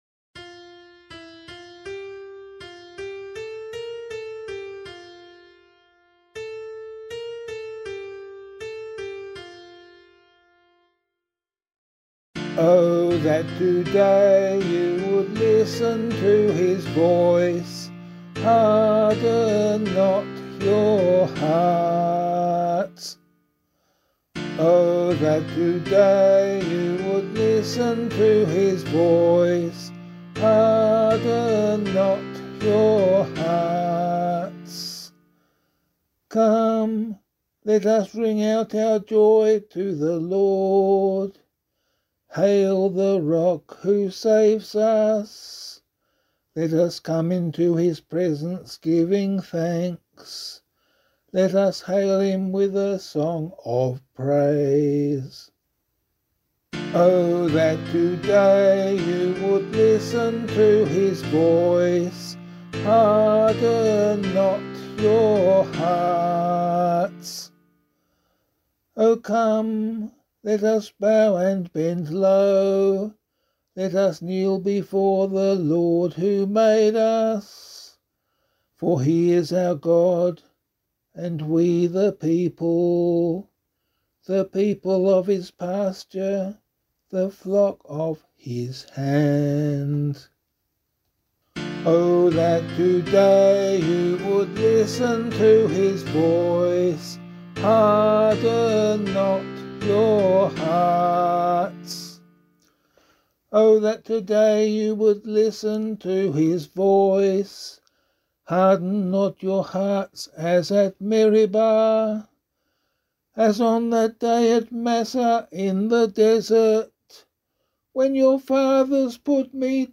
015 Lent 3 Psalm A [APC - LiturgyShare + Meinrad 1] - vocal.mp3